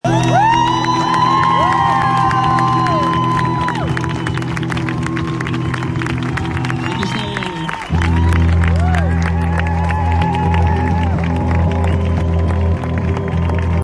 Claps Celebration